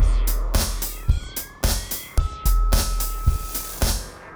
RemixedDrums_110BPM_39.wav